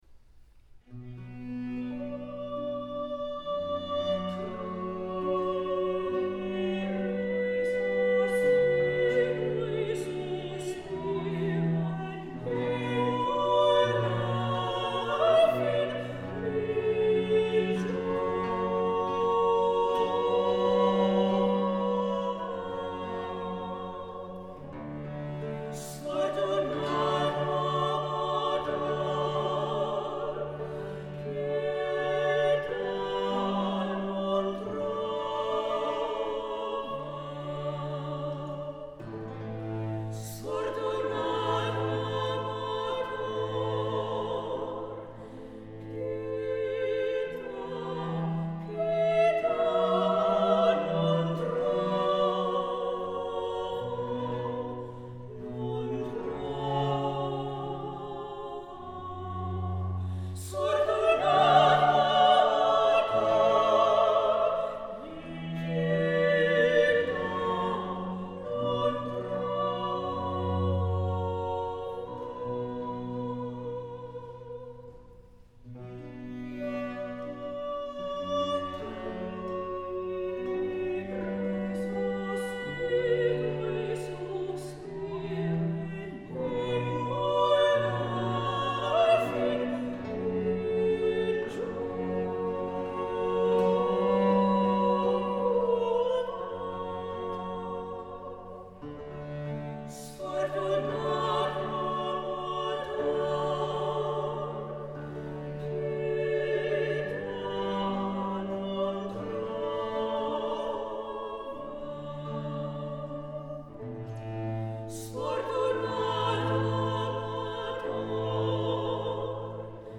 soloists
Recorded in performance, Nov. 13, 2006 Download